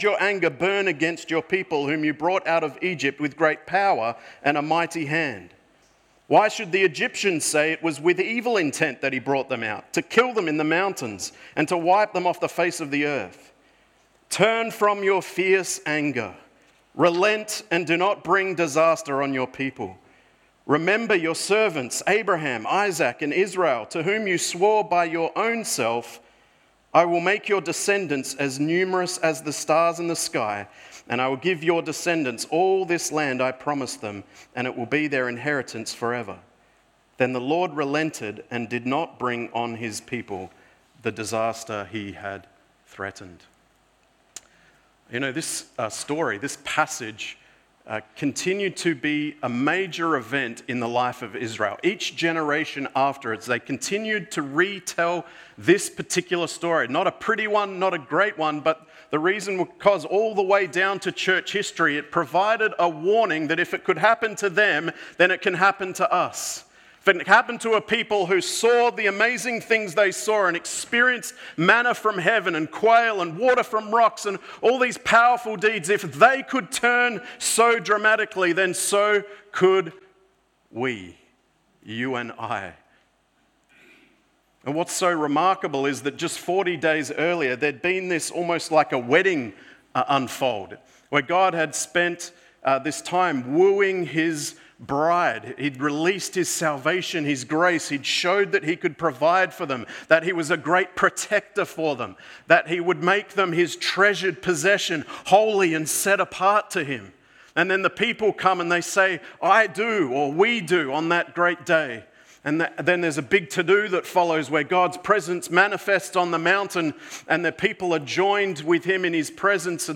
Sermons | Coolbellup Community Church
Sorry, first part of the message was not recorded